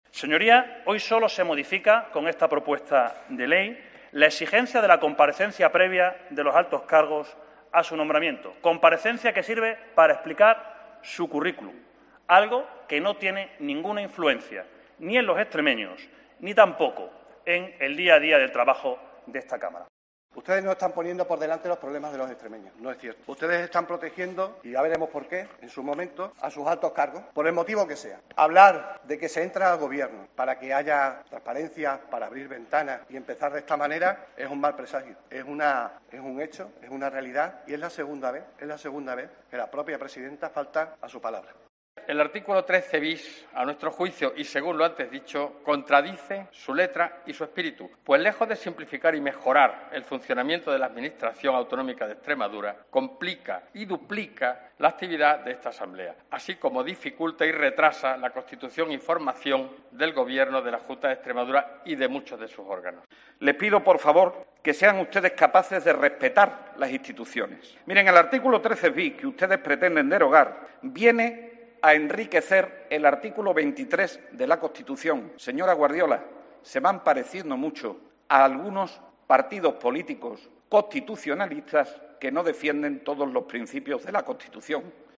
Intervenciones de los grupos parlamentarios PP/UP/VOX/PSOE